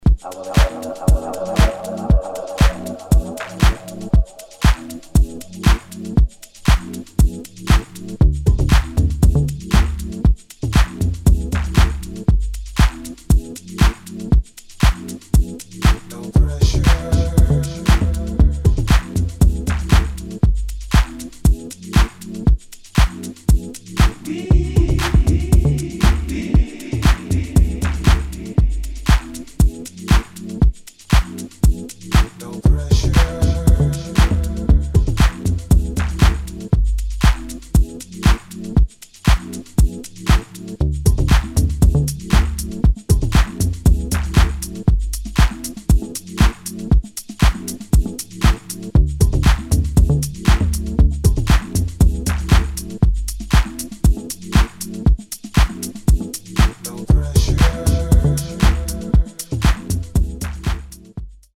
[ HOUSE / TECH HOUSE ]